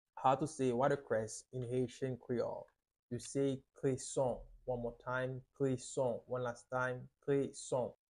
How to say "Watercress" in Haitian Creole - "Kreson" pronunciation by a native Haitian Teacher
“Kreson” Pronunciation in Haitian Creole by a native Haitian can be heard in the audio here or in the video below:
How-to-say-Watercress-in-Haitian-Creole-Kreson-pronunciation-by-a-native-Haitian-Teacher.mp3